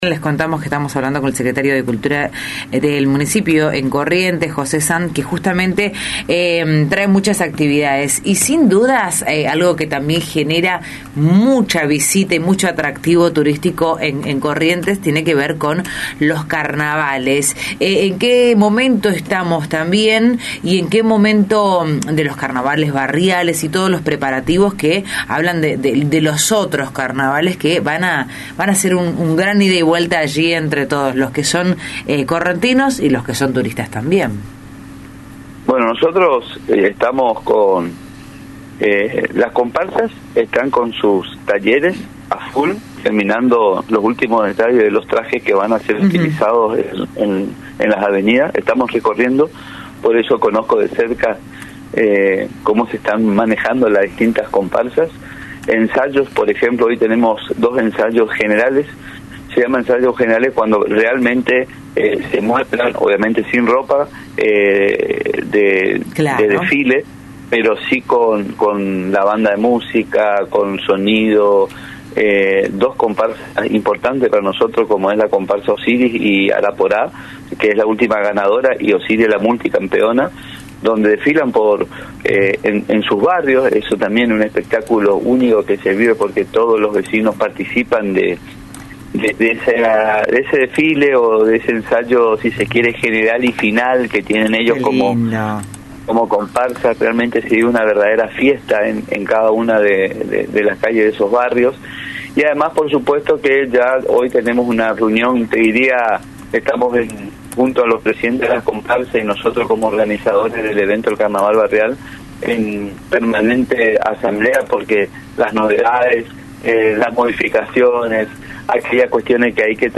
El secretario de Cultura de la Ciudad de Corrientes, José Sand, comentó al aire de Radio Facundo Quiroga que ya se están ultimando detalles para vivir los Carnavales correntinos.